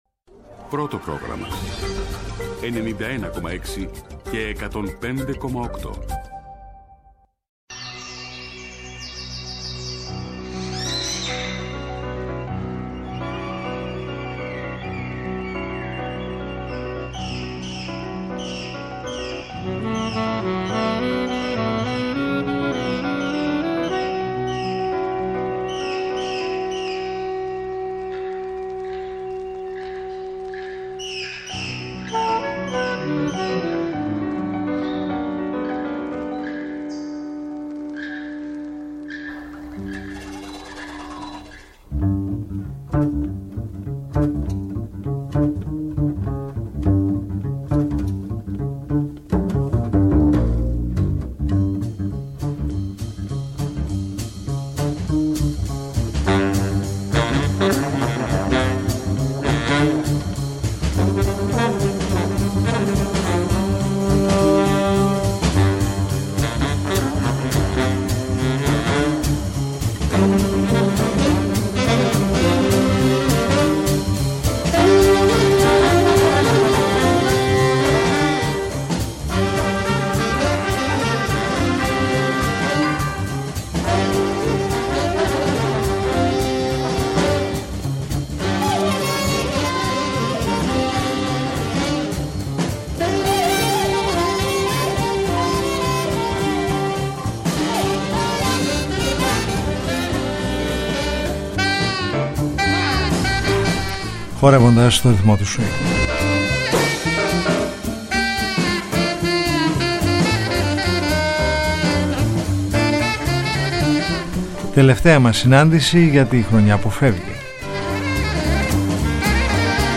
Η ποίηση συναντά μουσικές σελίδες μεγάλης ευαισθησίας μέσα απ’ την Διεθνή και Ελληνική τζαζ σκηνή αλλά και την αυτοσχεδιαζόμενη μουσική έκφραση.